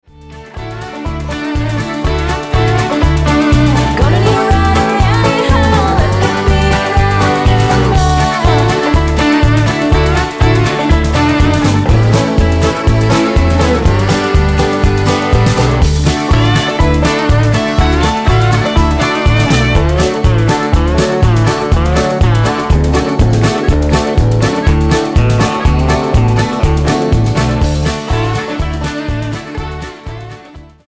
--> MP3 Demo abspielen...
Tonart:D mit Chor